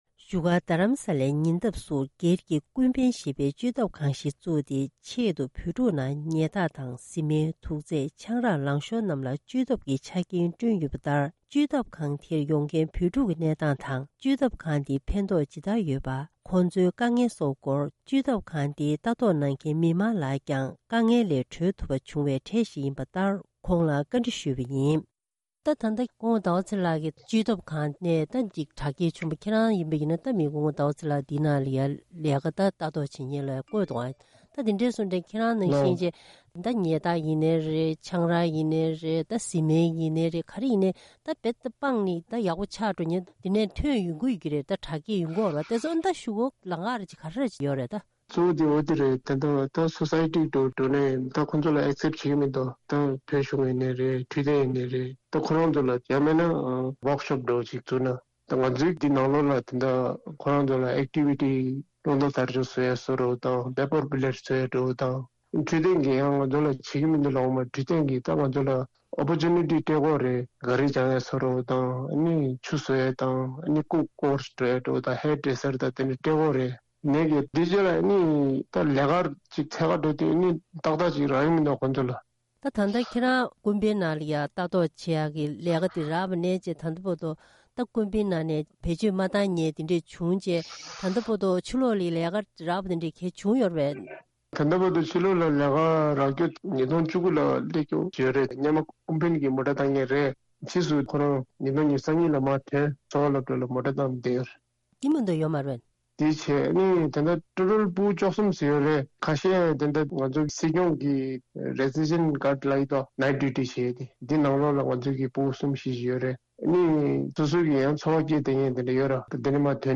བོད་ཕྲུག་ཁག་ཅིག་ཡག་པོ་དྲག་བསྐྱེད་བྱུང་སྟེ་བེད་སྤྱོད་རྩ་བ་ནས་མ་བཏང་མཁན་ཚོར། ད་དུང་སྤྱི་ཚོགས་དང་ཕ་མ་སྤུན་མཆེད་ཀྱིས་ཡིད་ཆེས་དང་ལས་ཀ་མི་སྤྲོད་པར་སྔོན་མའི་ལྟ་སྟངས་འཛིན་པ་ནི་གཅིག་འགྱུར་དཀའ་ངལ་ཆེན་པོ་ཞིག་ཡིན་པའི་སྐོར་སྙན་སྒྲོན་ཞུས་པ་ཞིག་གསན་རོགས་གནང་།